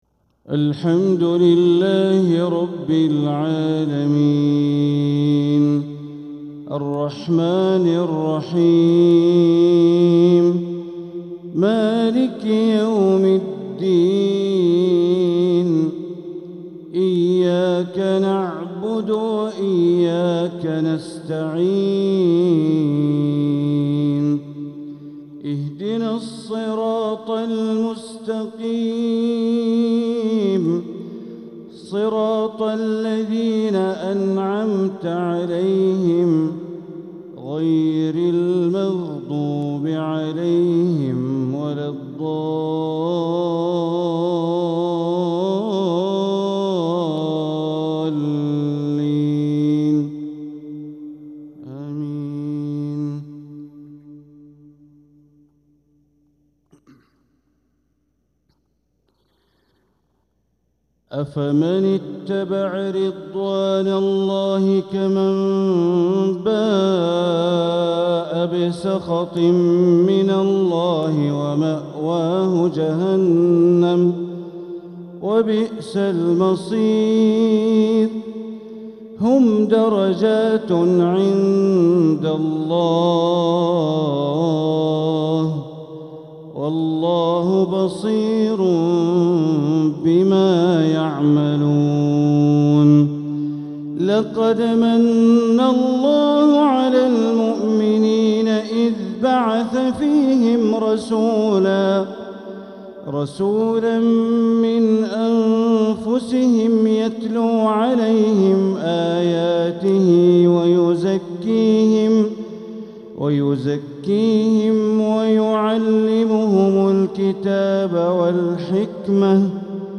تلاوة من سورتي آل عمران والتوبة مغرب الخميس 1-1-1447 > 1447هـ > الفروض - تلاوات بندر بليلة